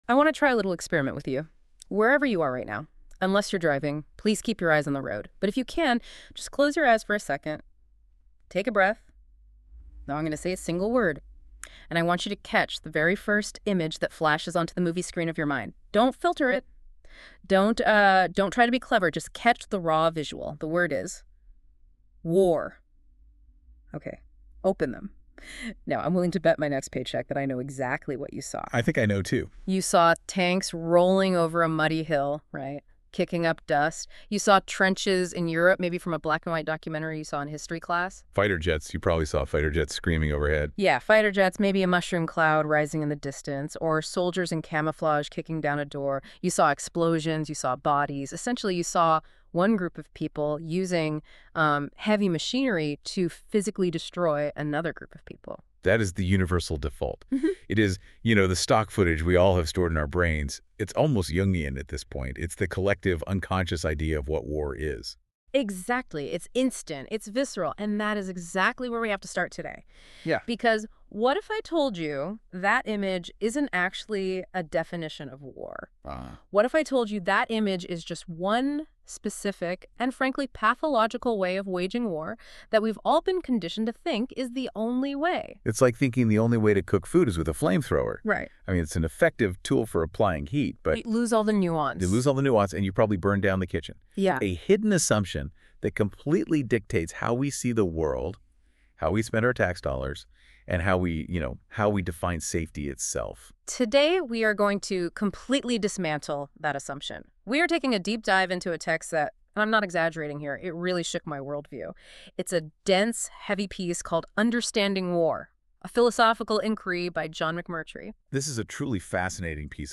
This Deep Dive discussion examines John McMurtry’s philosophical critique of the military paradigm, arguing that modern society has been conditioned to equate war solely with mass homicide. The text asserts that humans possess a natural psychological barrier against killing, which the military must systematically override through conditioning and dehumanization.